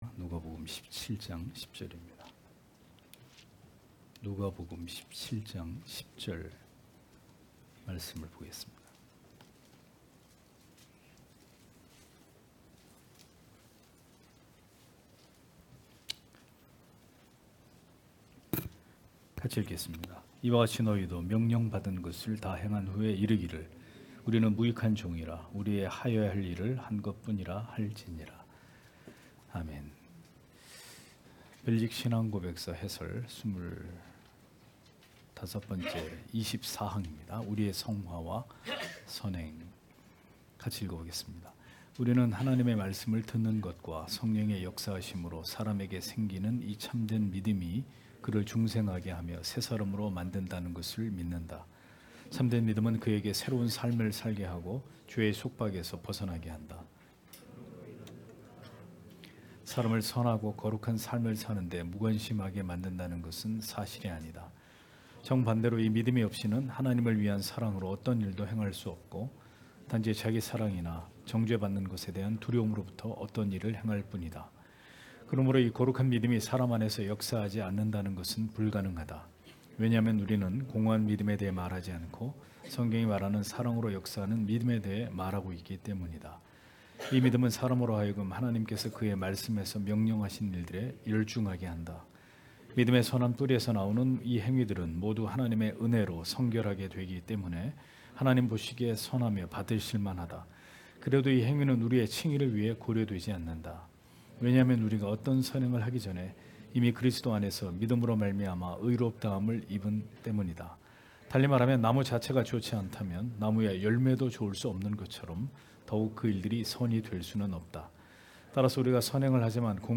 주일오후예배 - [벨직 신앙고백서 해설 25] 제24항 우리의 성화와 선행 (시편 143편 1-2절)
* 설교 파일을 다운 받으시려면 아래 설교 제목을 클릭해서 다운 받으시면 됩니다.